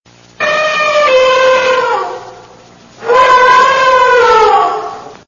دانلود آهنگ فیل از افکت صوتی انسان و موجودات زنده
دانلود صدای فیل از ساعد نیوز با لینک مستقیم و کیفیت بالا
جلوه های صوتی